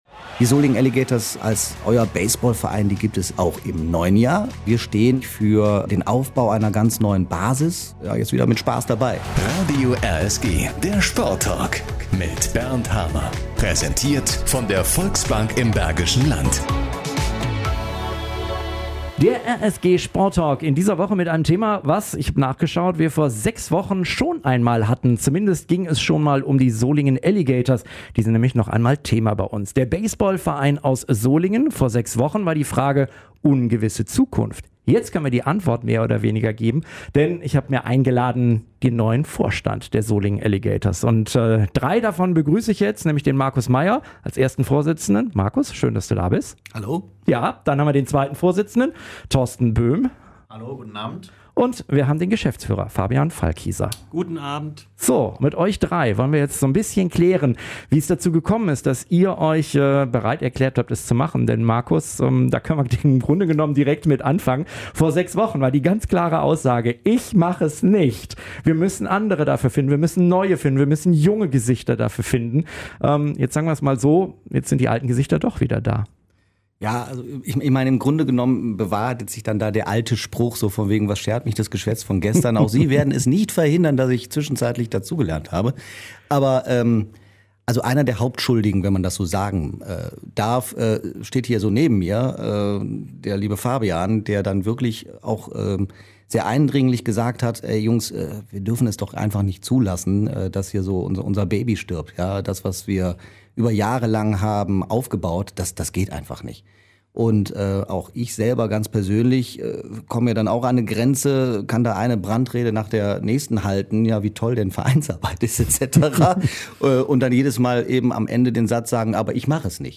Drei der fünf Vorstands-Mitglieder - die teilweise eine große Allis-Vergangenheit haben - sind in unserem RSG-Sporttalk-Podcast zu Gast (30.12.2021):